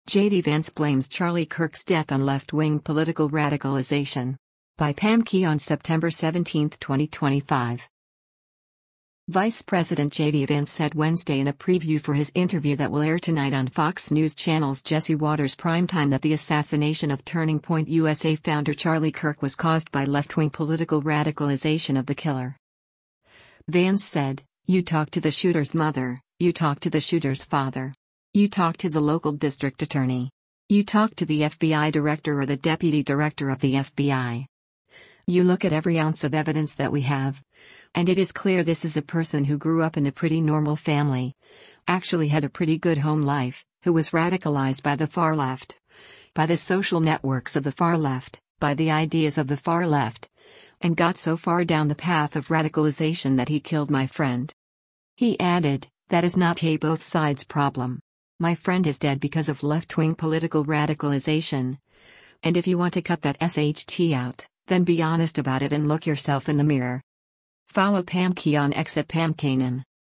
Vice President JD Vance said Wednesday in a preview for his interview that will air tonight on Fox News Channel’s “Jesse Watters Primetime” that the assassination of Turning Point USA founder Charlie Kirk was caused by “left-wing political radicalization” of the killer.